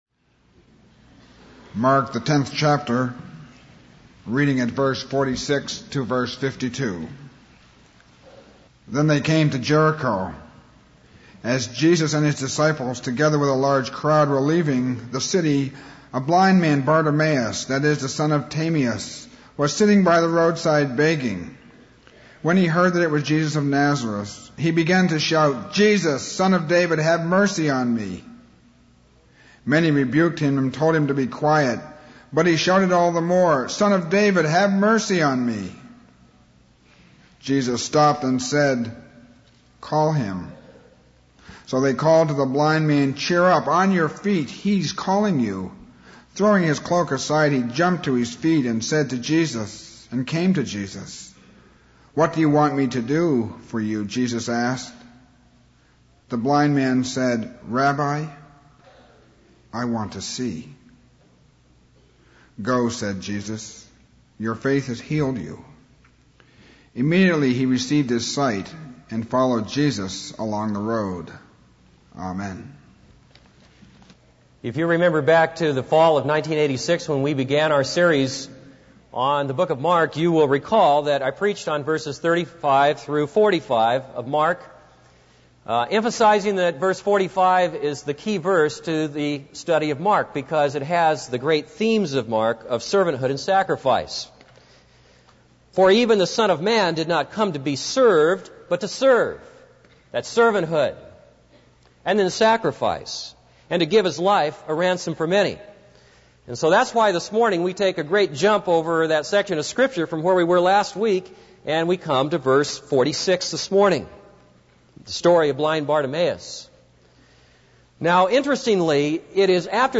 This is a sermon on Mark 10:46-52.